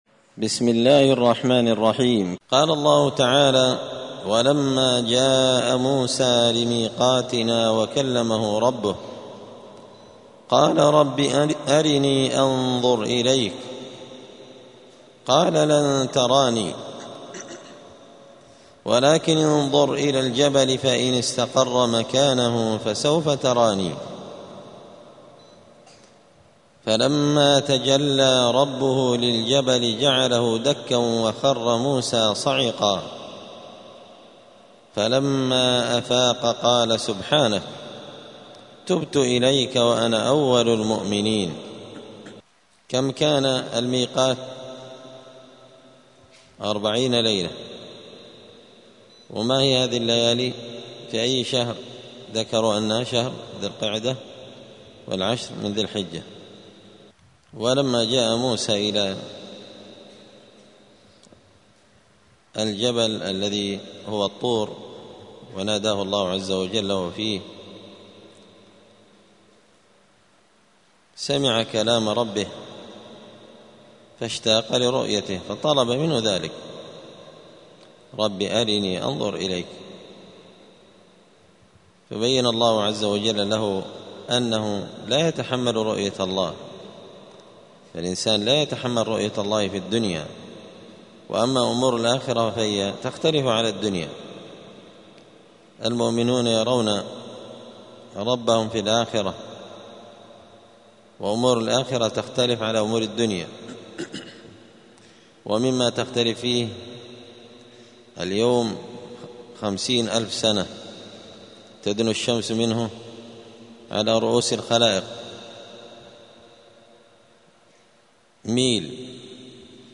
📌الدروس اليومية